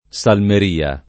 salmeria [ S almer & a ]